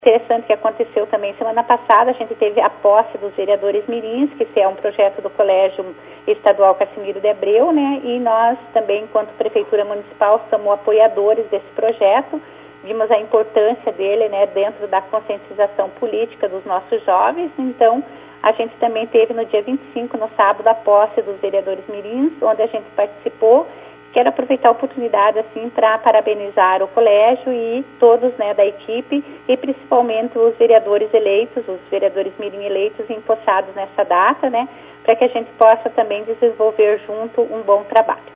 A prefeita de Porto Vitória Marisa de Fátima Ilkiu de Souza esteve prestigiando a cerimônia de posse dos Vereadores Mirins, e destacou a importância dos jovens na vida pública. Em entrevista para o jornalismo da Rádio Colmeia, a prefeita fez questão de destacar o projeto e a valorização dos jovens na vida política.